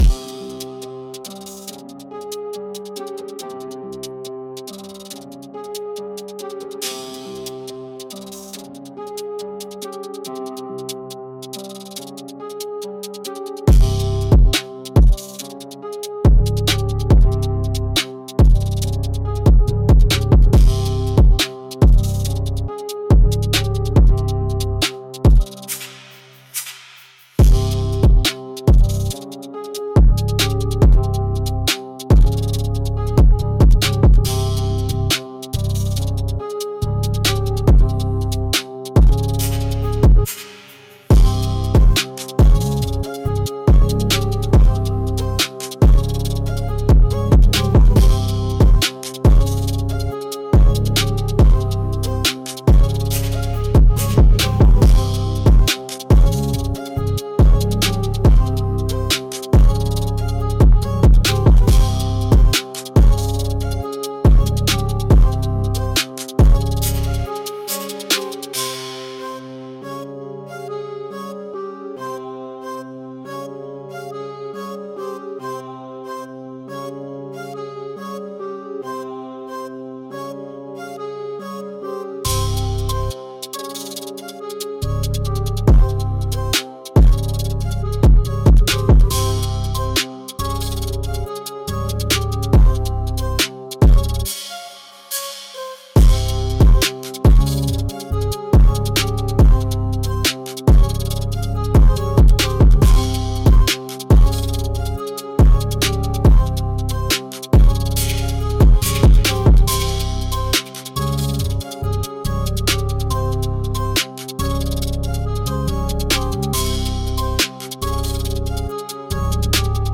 Instrumental - - Real Liberty media .